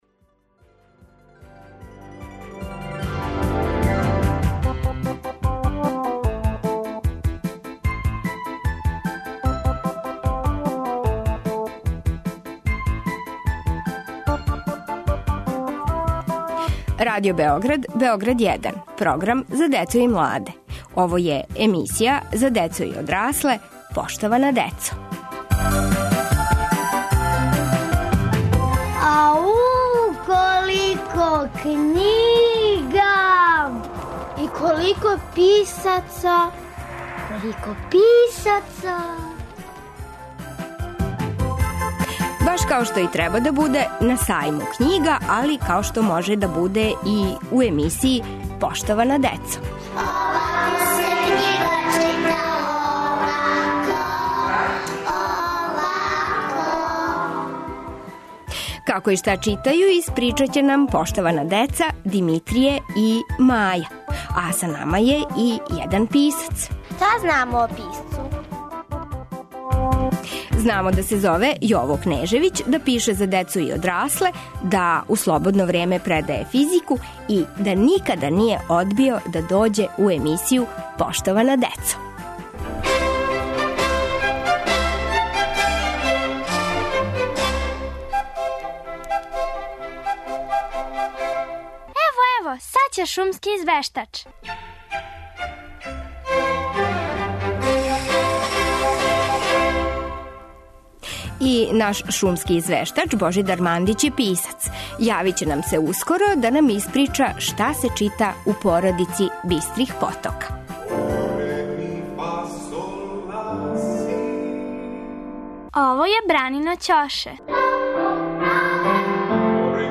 Били смо у Новом Пазару, и тамо пронашли велико књишко благо и врло заинтересовану децу.